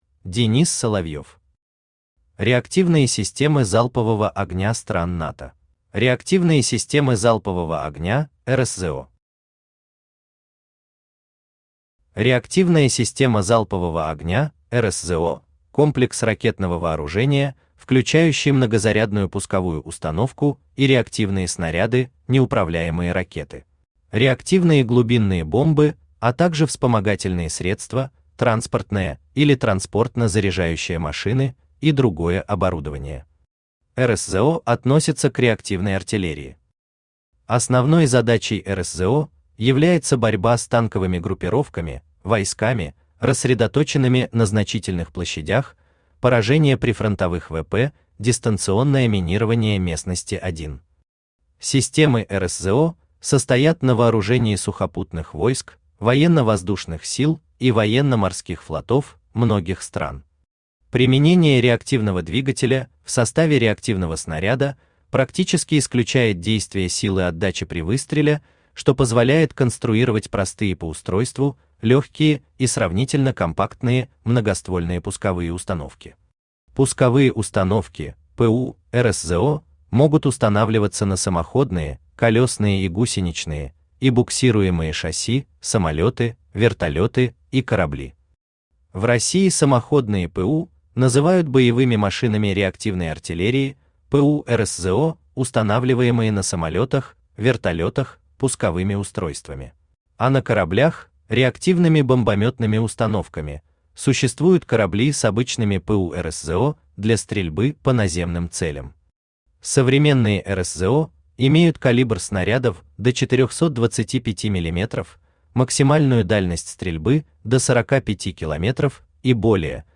Аудиокнига Реактивные системы залпового огня стран НАТО | Библиотека аудиокниг
Aудиокнига Реактивные системы залпового огня стран НАТО Автор Денис Соловьев Читает аудиокнигу Авточтец ЛитРес.